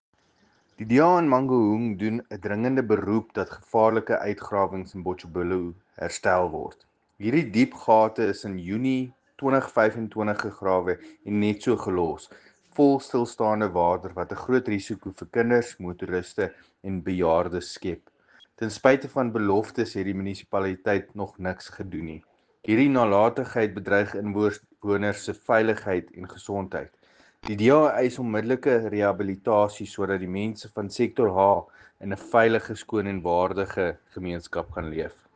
Afrikaans soundbite by Cllr Paul Kotzé.